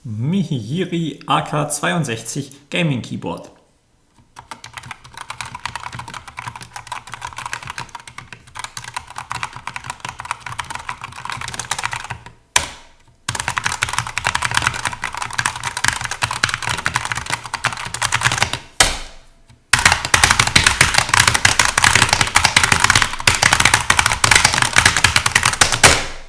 Die Tastatur ist gut hörbar, erlaubt dezentes mechanisches Nachhallen, und klappert hell vor sich hin, bleibt aber in einem hinnehmbaren Bereich, gerade hinsichtlich des Preises – und schneidet gar nicht so viel schlechter ab als eine Sharkoon SGK50 S4 der ersten Generation mit dünner Schaumstoff-Schicht, die Nachhall reduziert und etwas heller, aber auch vollere Anschläge erzeugt.
Die einfache Konstruktion hat akustisch klare Auswirkungen
MIHIYIRY AK62 Mechanische Gaming Tastatur (Outemu Red)